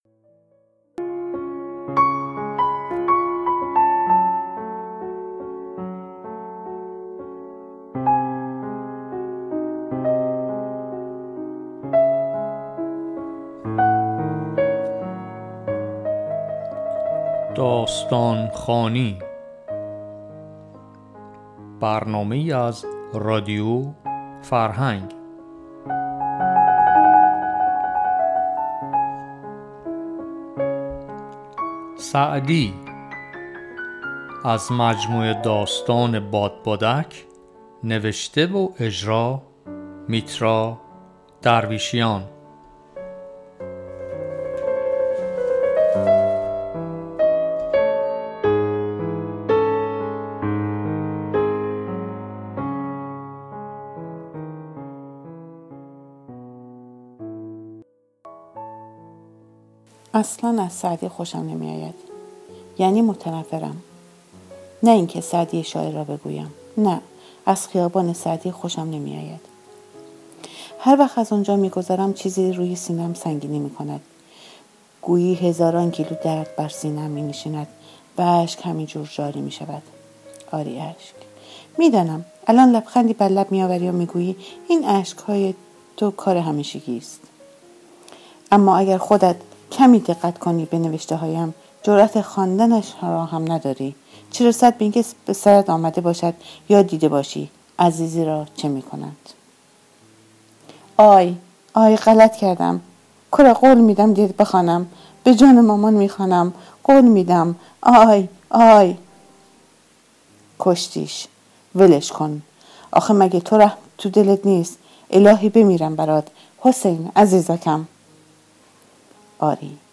قصه خوانى برنامه اى از رادیو فرهنگ